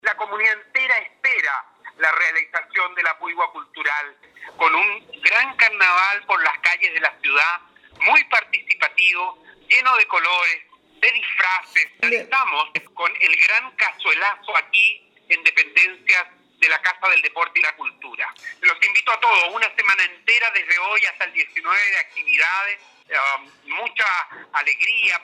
El colorido carnaval finalizó en la casa del Deporte y la Cultura, donde se dio pie a Jornada que finalizó con la fiesta Gastronómica y Artesanal “El Cazuelazo”, a la cual se refirió con satisfacción el alcalde de la comuna, José Luis Queipul.